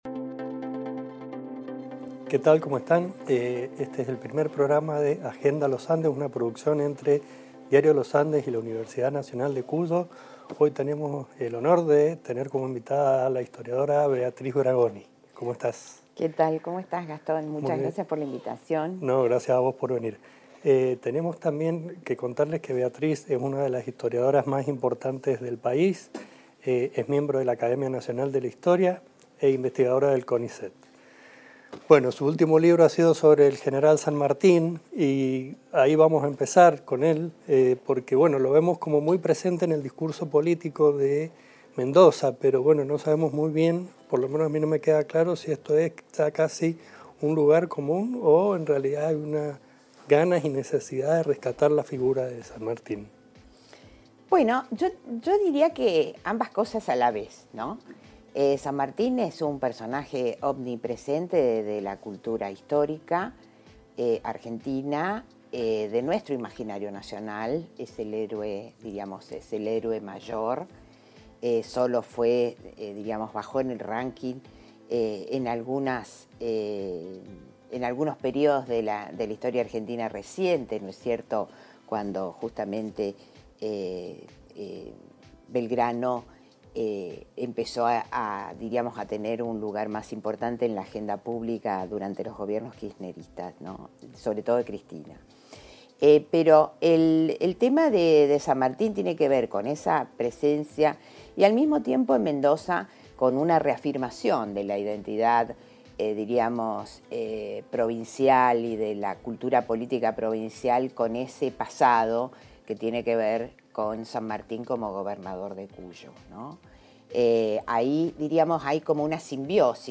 Agenda Los Andes es un programa de Diario Los Andes y la UNCuyo sobre temas de interés general. El ciclo reunirá a los principales referentes locales de diversos ámbitos en entrevistas íntimas.